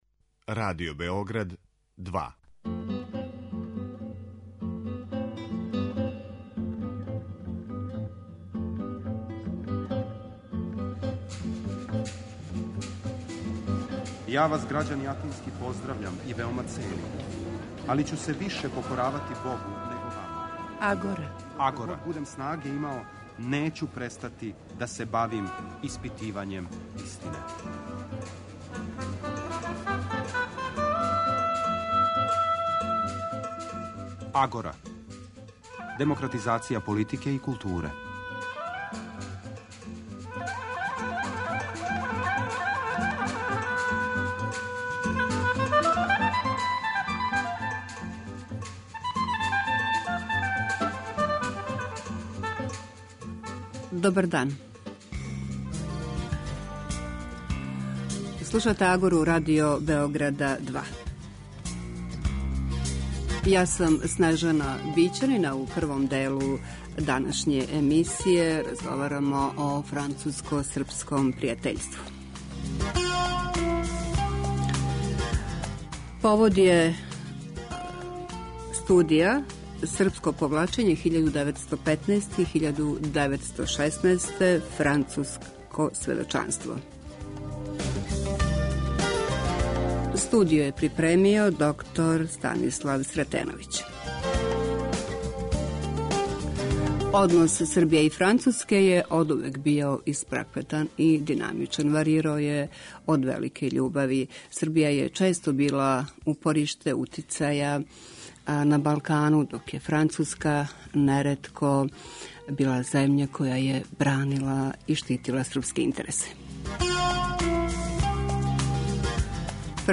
Радио-магазин